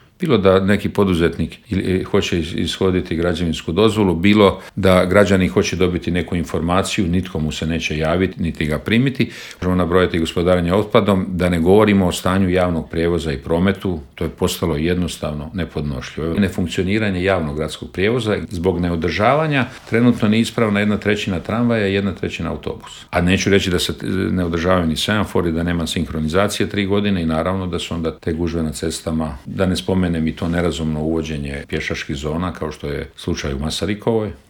ZAGREB - Predsjednik stranke Plavi Grad, zastupnik u Gradskoj skupštini i kandidat za gradonačelnika Grada Zagreba Ivica Lovrić u Intervjuu Media servisa osvrnuo se na na ključne gradske probleme poput opskrbe plinom, Jakuševca i prometnog kolapsa.